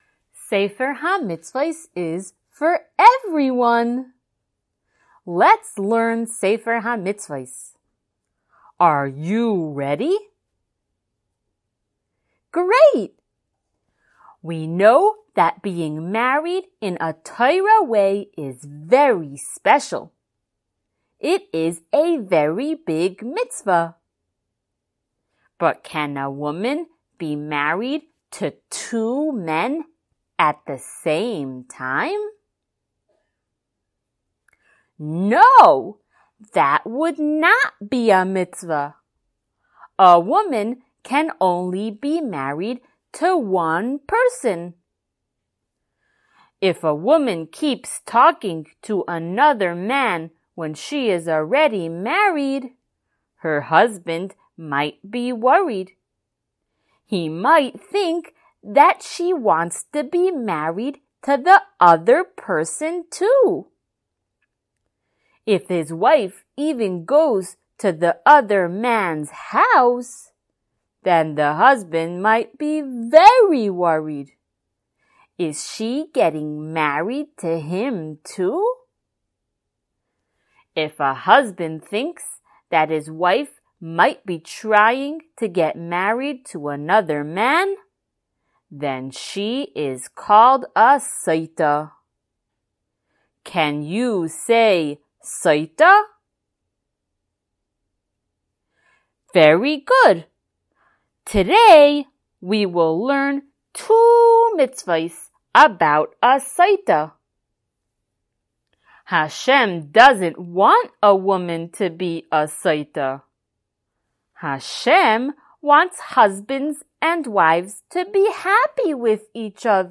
Color Shiur #86!